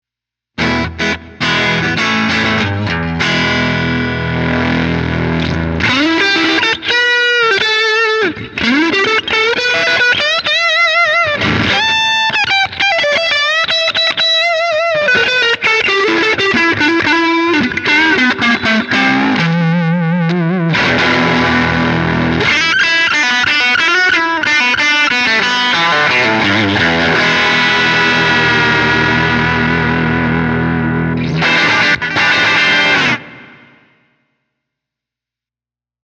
All audio clips are recorded with a Marshall JCM900 head, set on an extremely clean tone. The cabinet used is a 2×12 openback with Celestion Creamback 75 speakers.
Clean sound, no effects added
Guitar: Fender
Mode: Deluxe
Gain: 9/10